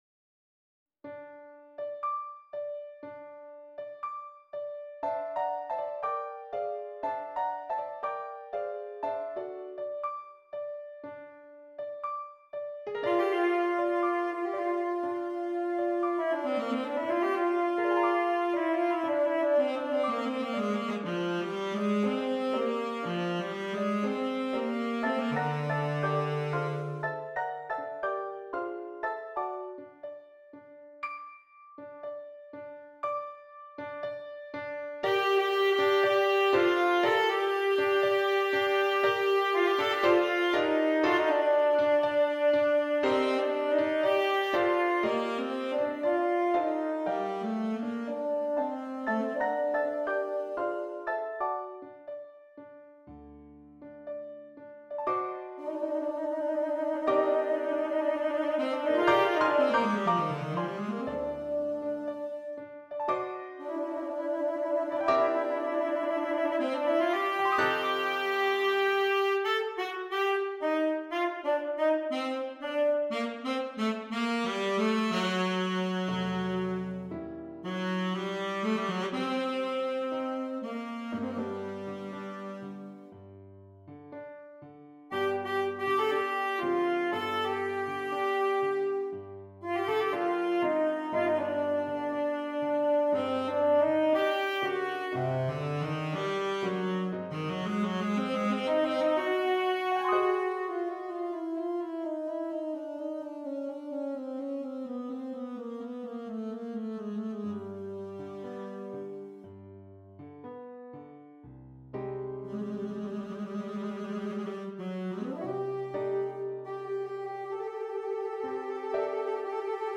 Alto Saxophone and Keyboard